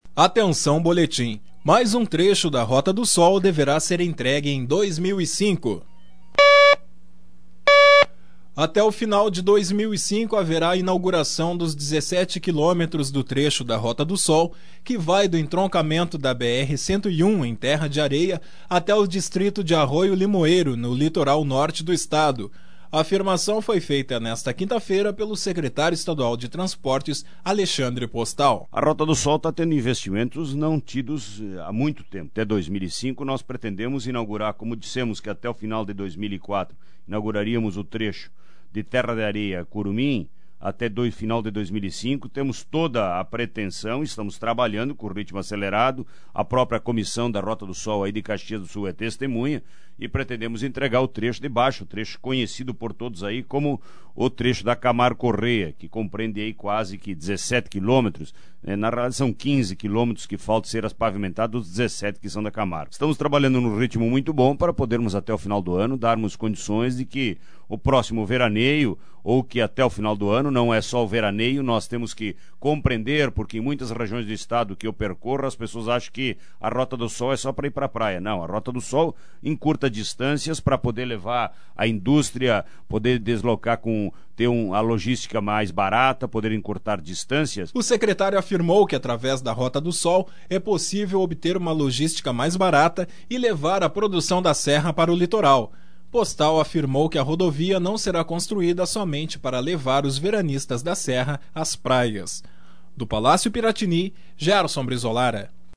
Até o final de 2005, haverá a inauguração dos 17 quilômetros do trecho da Rota do Sol, que vai do entroncamento da BR-101 em Terra de Areia até o distrito de Arroio Limoeiro, no litoral norte do Estado. Sonora: secretário estadual de Transportes, Alexa